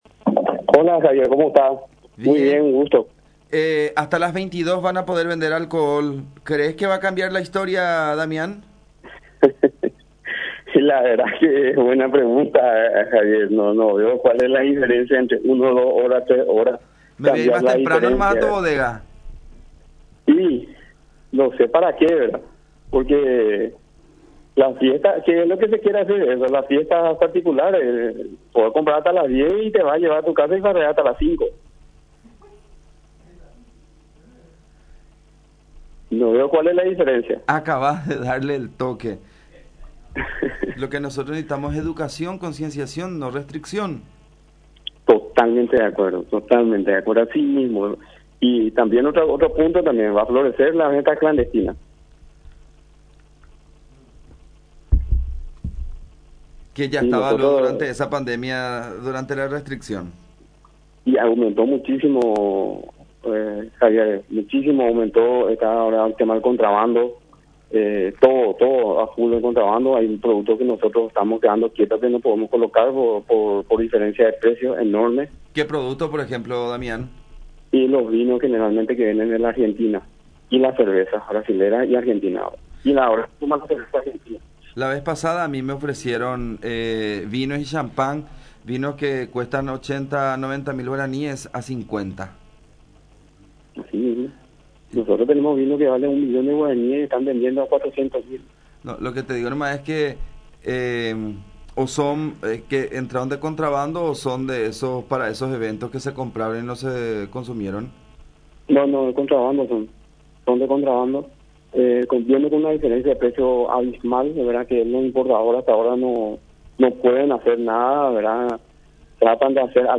en charla con La Unión